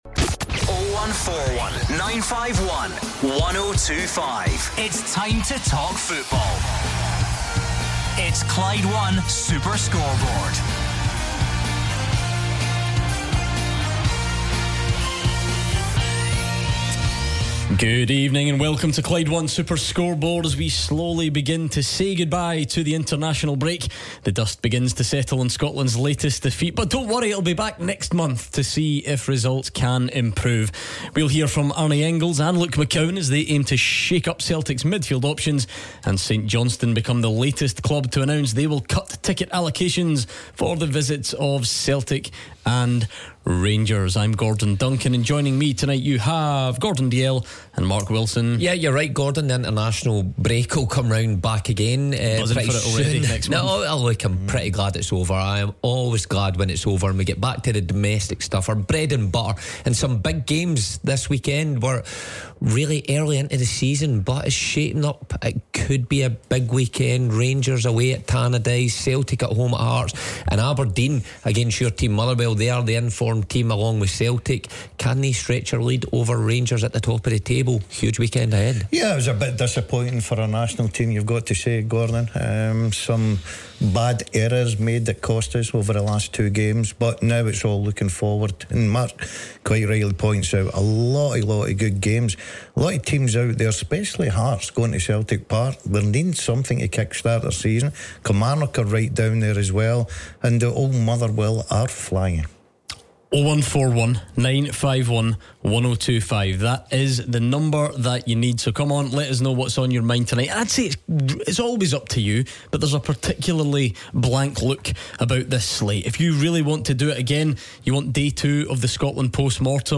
live at the Signal Iduna Park. Also discussing Ianis Hagi's return to the Rangers first team and the Scotland squad announcement